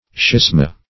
Search Result for " schisma" : The Collaborative International Dictionary of English v.0.48: Schisma \Schis"ma\, n. [L., a split, separation, Gr. schi`sma: cf. F. schisma.